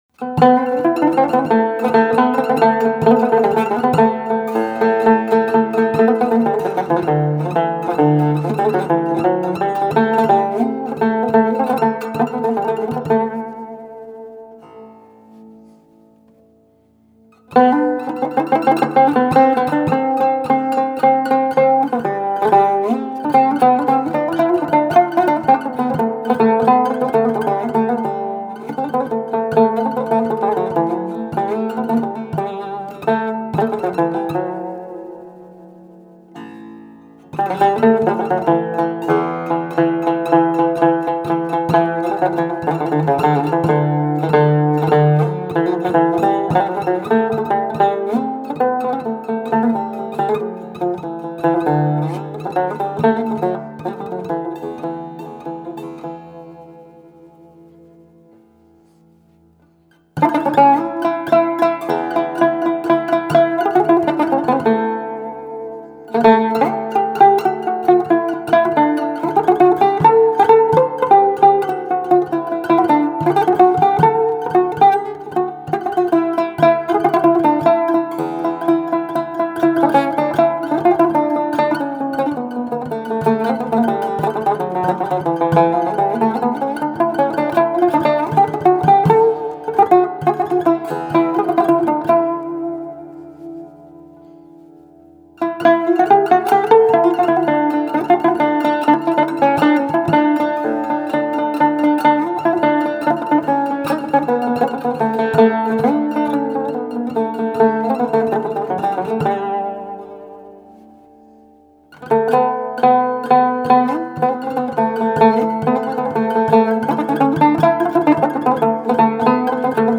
Genre: Turkish & Ottoman Classical.
Joint improvisation, transition to Huseyni makam
Studio: Aria, Üsküdar, Istanbul